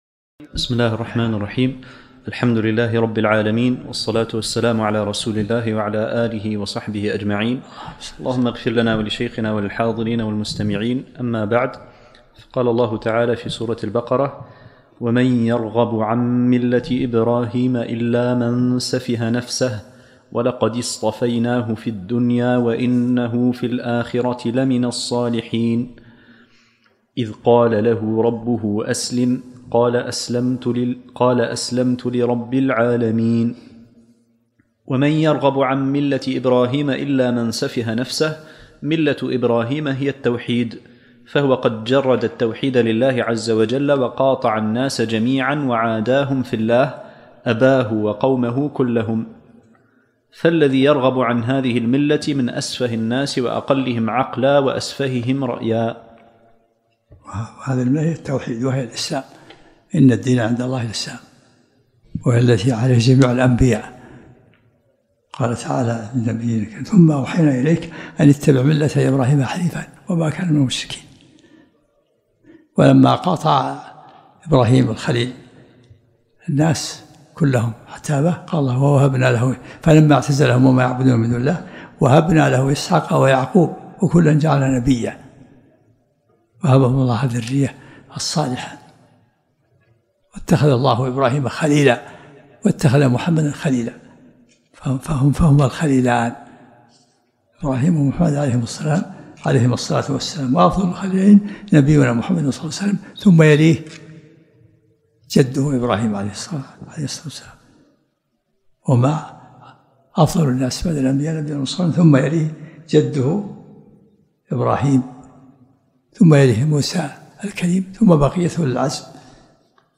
الدرس العاشرمن سورة البقرة